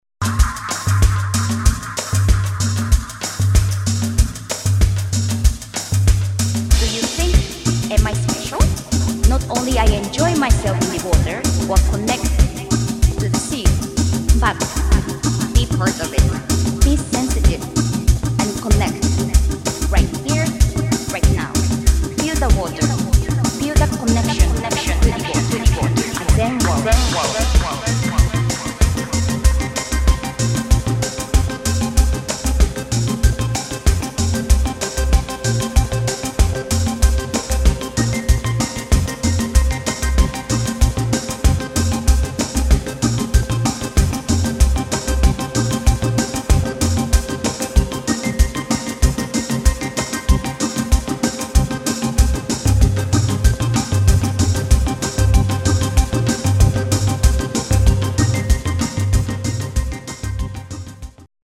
[ BALEARIC / DISCO / DOWNTEMPO ]
UKバレアリック・デュオ